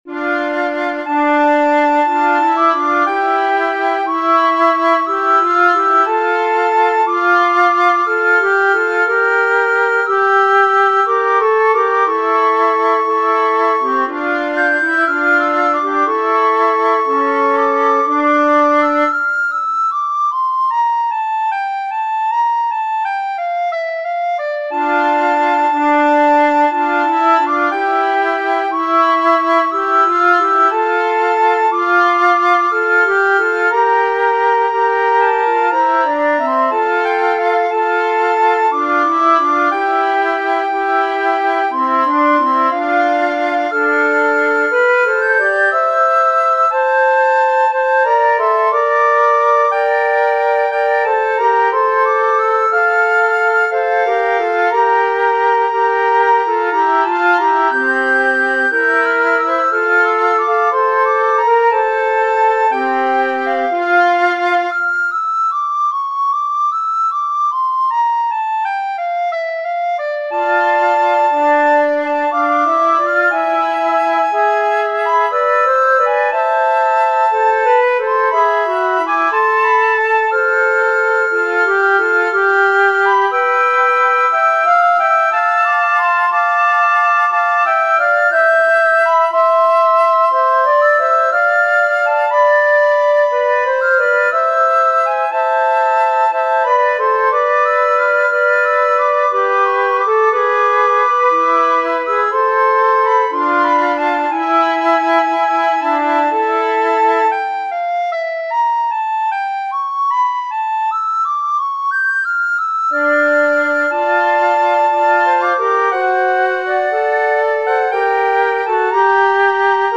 編曲（PCで演奏）：
ヘンデルのソナタ Op.1-4 Largetto（Soprano Recorder＋２Flutes）